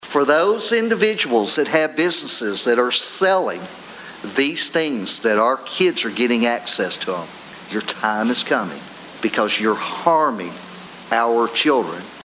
8th District State Representative Walker Thomas, 9th District State Representative Myron Dossett, and 3rd District State Senator Craig Richardson shared some of those Thursday night at a Town Hall.